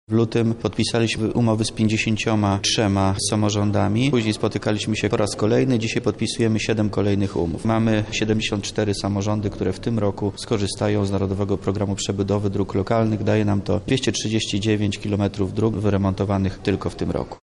W tym roku na podpsianiu takich umów spotykamy się już po raz czwarty – mówi Wojciech Wilk, wojewoda lubelski.